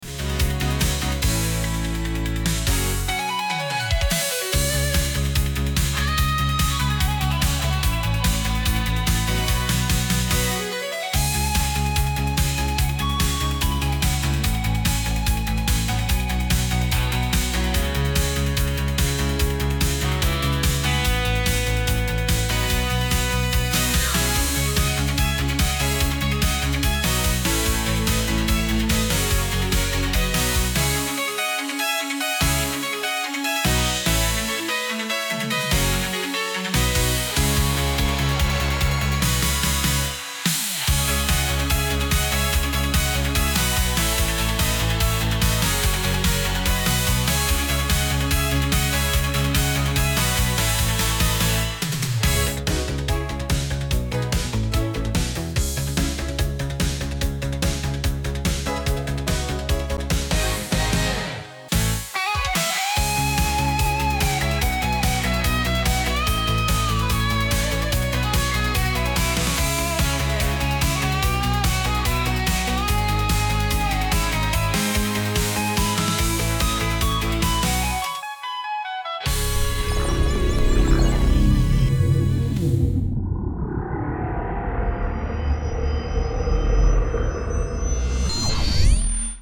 …kur Europa e pa dizajnin e skenës së Eurovision (Bazel 2025) sakaq u mallëngjye, u ligështua, u dorëzua, tha “po” – anise festivali duhet pritur dhe 4-5 muaj, me rastin e shpalosjes së dizajnit skenik, ne ia kushtuam 1 melodi dizajnit të tyre impresiv, e vumë skenën në punë, me drita e me gjithçka, gjoja sikur dhe këngët kur marrin pjesë gjatë festivalit të vërtetë : )
Funny compilation – Greetings to Switzerland, to Eurovision SC Basel 2025 – Eurovision rhymes very well with Sky Division, almost you can’t have one without the other… so “Europe says Yes”, and also why have Eurovision at all if you’re not going to have Eurodance style songs, how can you have a contest in the heart Europe without Eurodance, without Eurobeats, without Eurotrance, without Eurodisco, without Eurohouse, it’s almost “weird”, there are so many music styles with “Euro”, so why an Eurovision without Euro… naaah just kidding : )